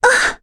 Laias-Vox_Damage_01.wav